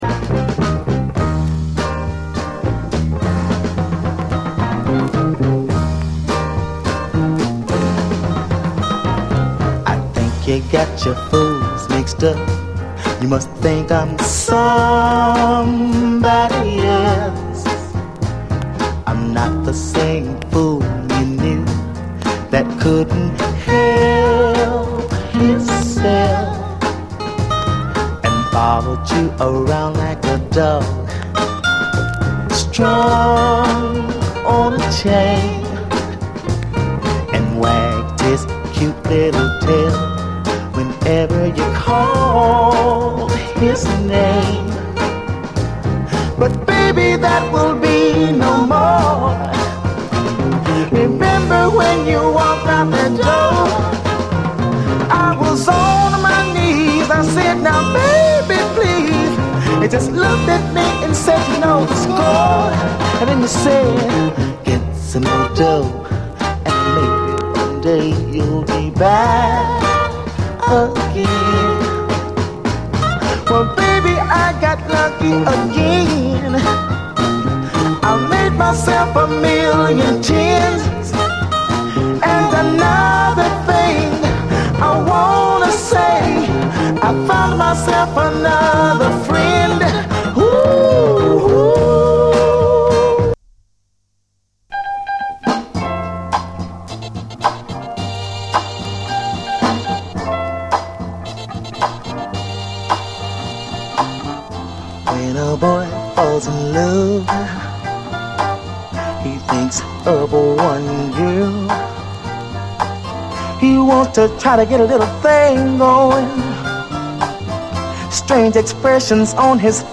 SOUL, 60's SOUL
チカーノ〜ローライダーにも愛される60'Sスウィート・ソウル大名盤！
泣きのローライダー・ソウル群に胸を締め付けられる黄金の一枚！
STEREO、A面曲順ミスプリントのあるラベル、U.S.ORIGINAL盤！